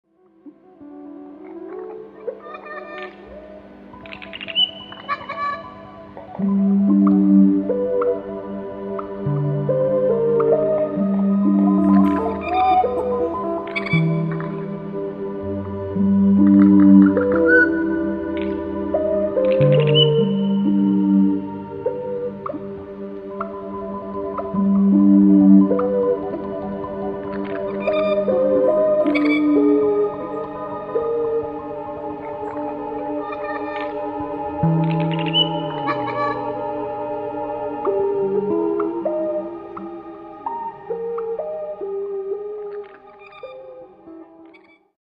Australian, Classical, Keyboard